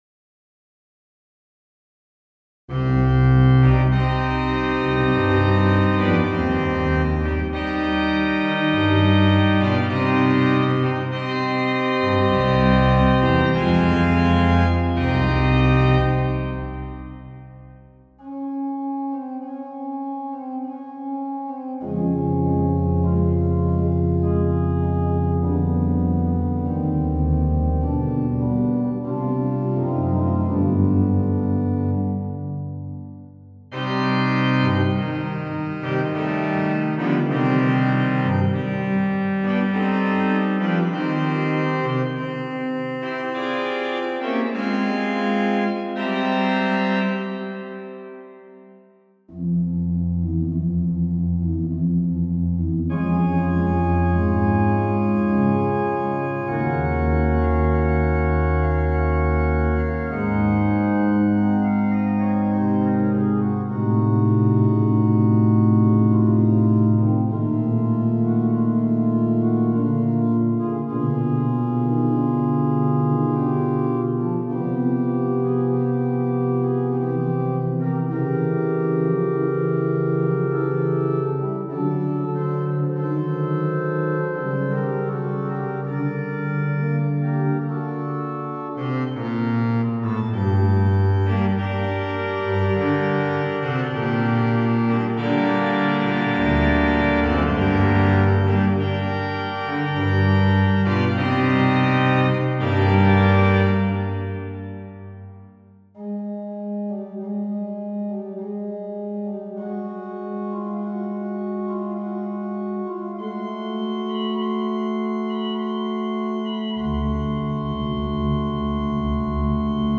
Souvigny orgue virtuel, liste 2, janvier février 26